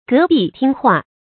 隔壁听话 gé bì tīng huà
隔壁听话发音
成语注音 ㄍㄜˊ ㄅㄧˋ ㄊㄧㄥ ㄏㄨㄚˋ